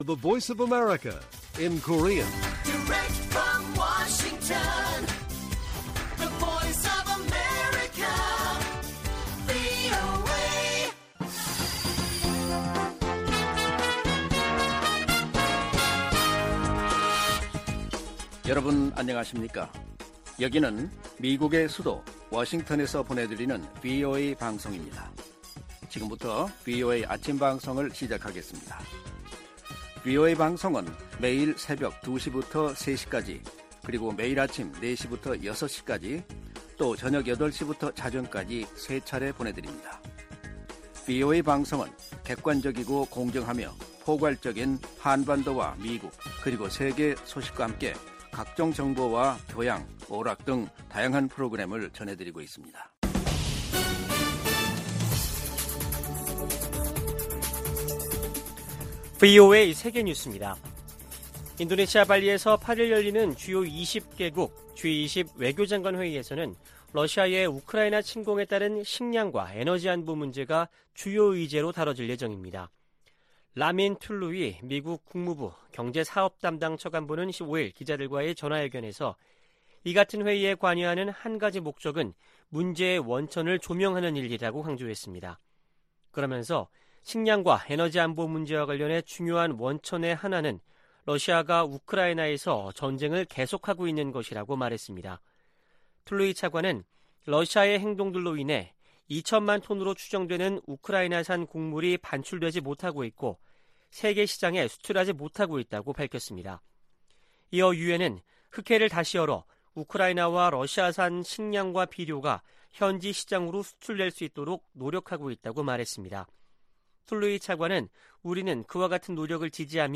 세계 뉴스와 함께 미국의 모든 것을 소개하는 '생방송 여기는 워싱턴입니다', 2022년 7월 7일 아침 방송입니다. '지구촌 오늘'에서는 러시아군이 우크라이나 도네츠크 주에 공세를 강화하는 소식, '아메리카 나우'에서는 시카고 총격범에게 살인 7건 등 혐의가 적용된 이야기 전해드립니다.